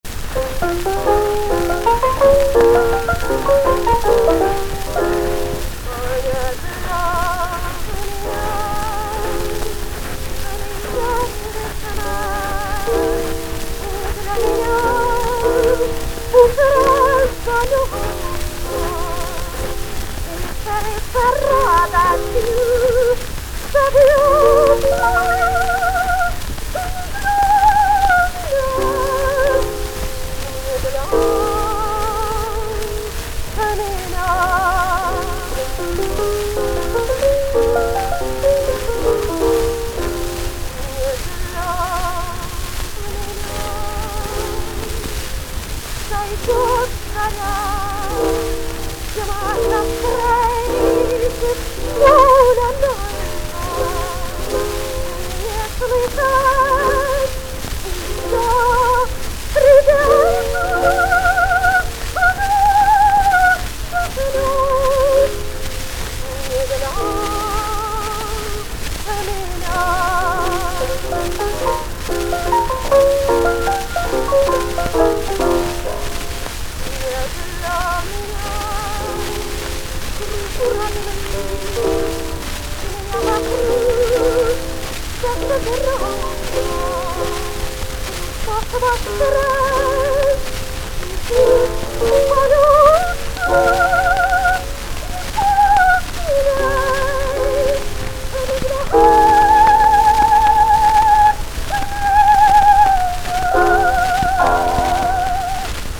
Место записи: С.-Петербург | Дата записи: 1900